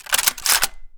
gun_rifle_cock_04.wav